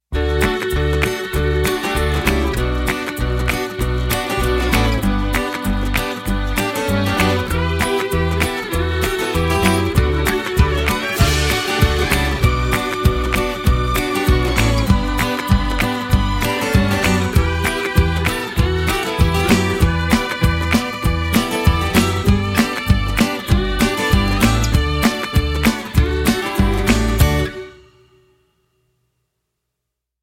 首页 背景音乐 其他音乐 正文